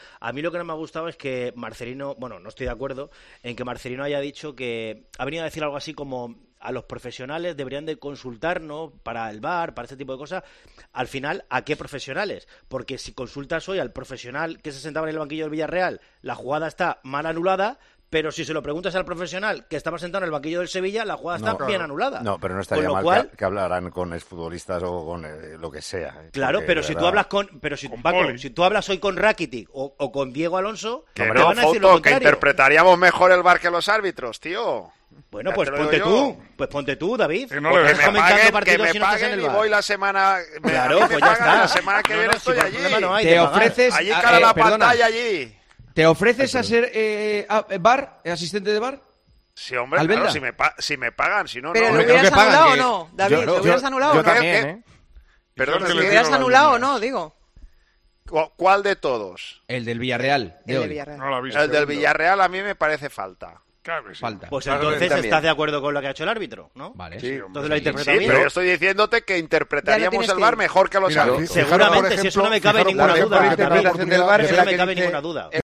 El debate en Tiempo de Juego